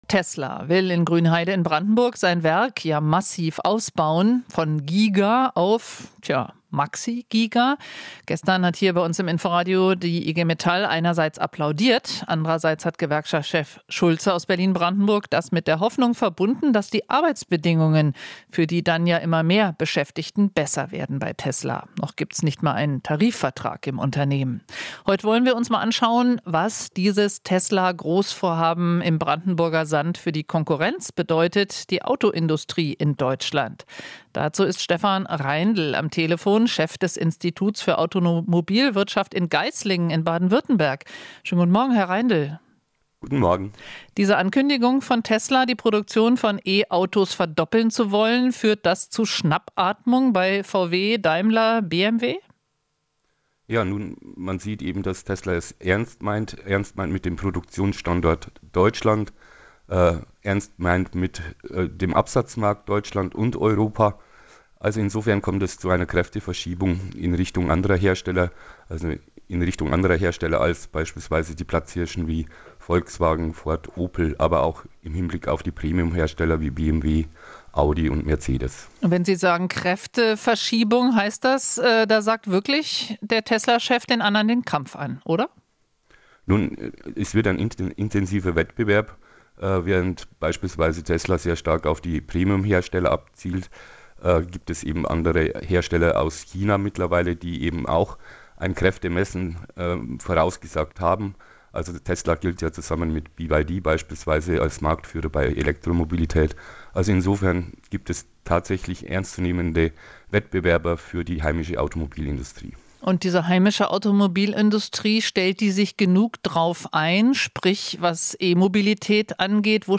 Interview - Was bedeutet der Tesla-Ausbau für die deutsche Autoindustrie?